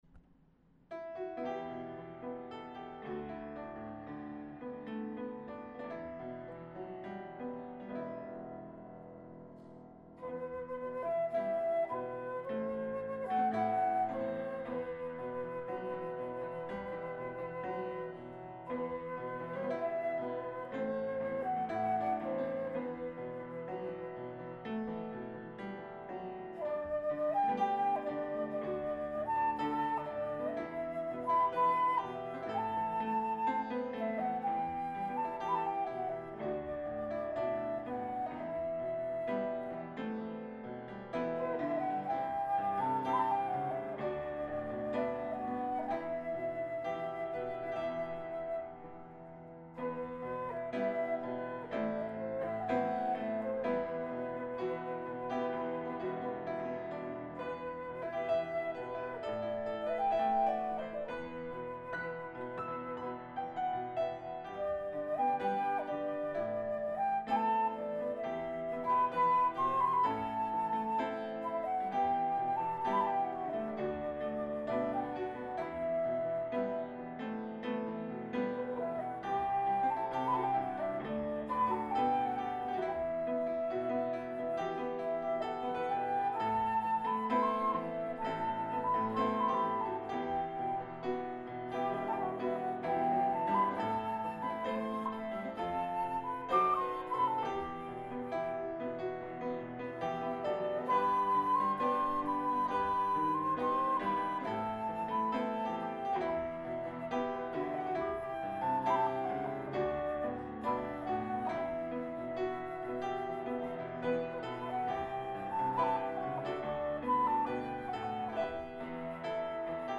פסנתר: אנוכי..
חליל צד